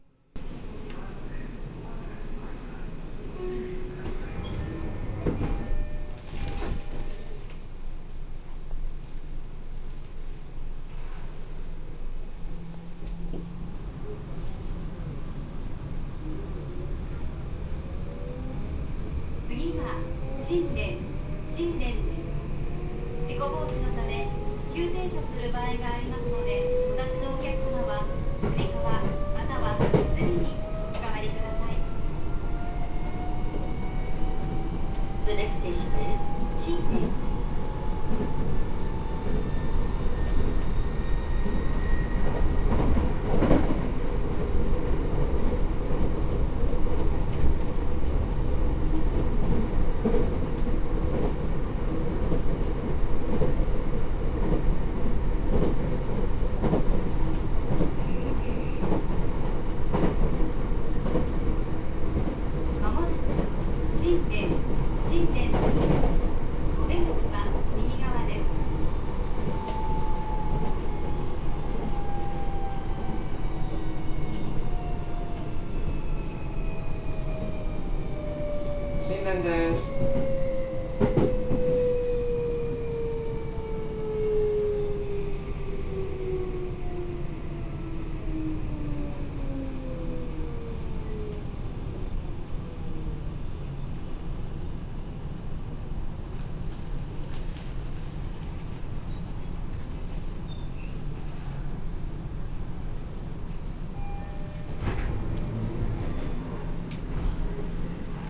〜車両の音〜
・70000系走行音
【伊勢崎線】獨協大学前→新田（3分56秒：1.25MB）
ドアチャイムが「209系チャイム」になり、車内放送および車載発車放送のアナウンサーが変更になってしまったのはリバティと同様。PMSMであることも13000系と同様ですが、13000系だと車内放送の声は従来の方が担当しています。